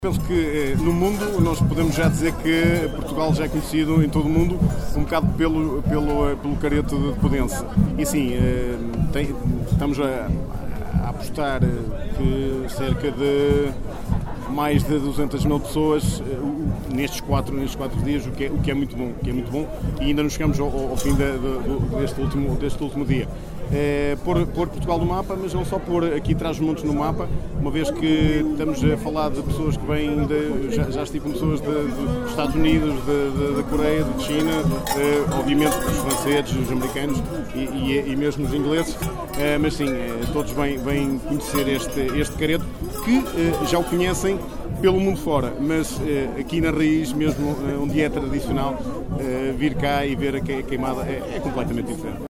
Estima-se que, ao longo dos quatro dias, tenham passado por Podence cerca de 200 mil pessoas, como sublinha o presidente da Câmara Municipal de Macedo de Cavaleiros, Sérgio Borges: